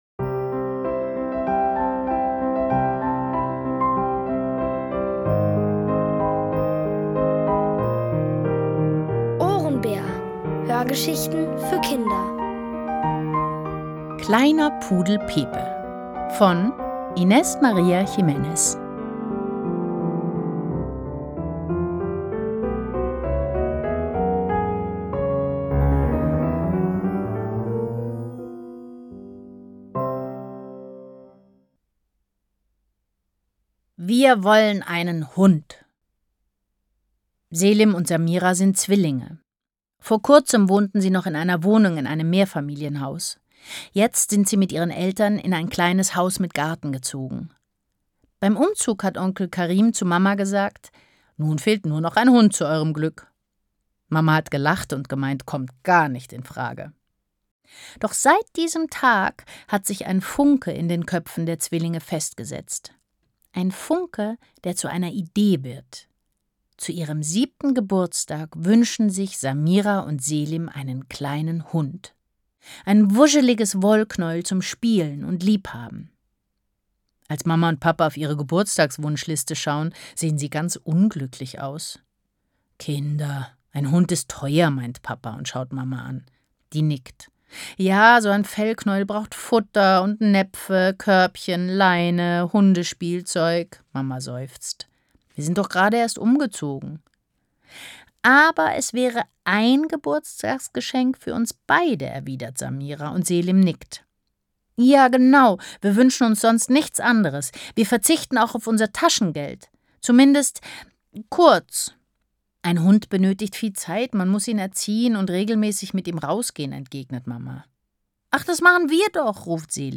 Von Autoren extra für die Reihe geschrieben und von bekannten Schauspielern gelesen.
Es liest: Nina Kunzendorf.